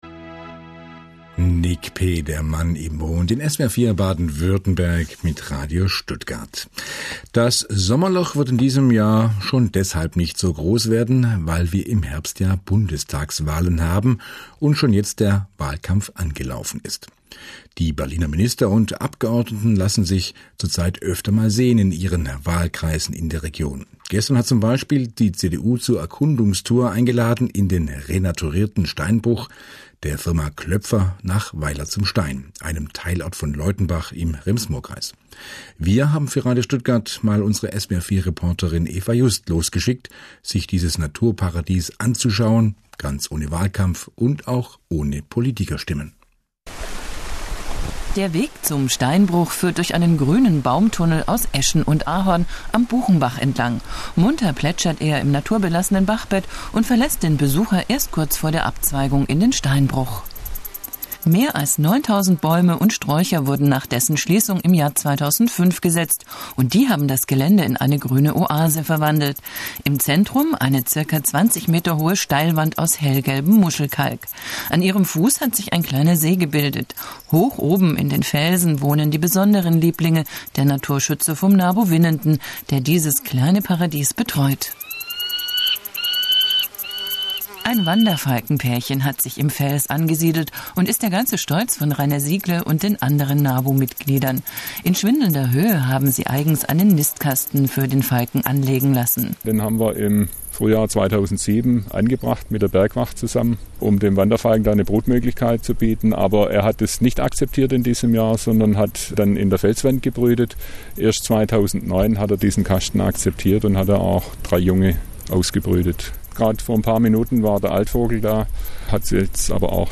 Besuch der Umweltministerin im ehemaligen Steinbruch der Fa. Klöpfer in Weiler zum Stein am 6.8.2009
Der daraus entstandene Radiobeitrag wurde am 6.8.2009 gesendet.